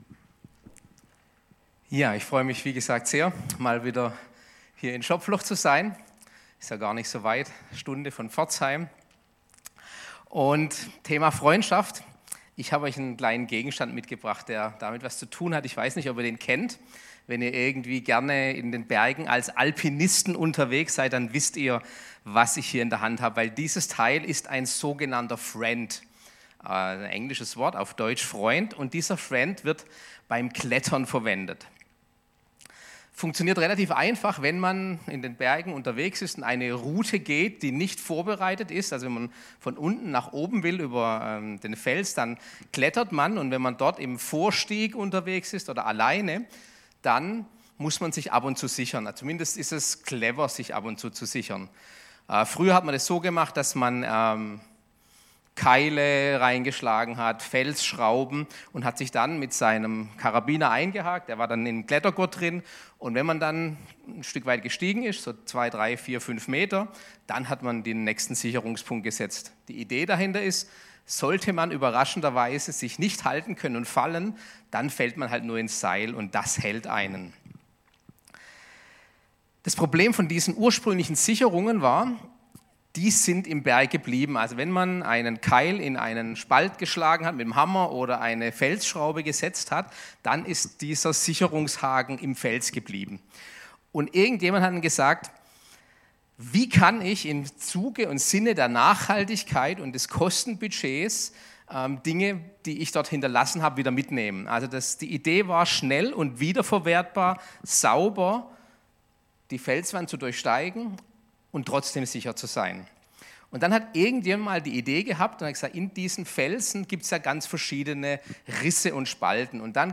Go In Gottesdienst am 18.02.2024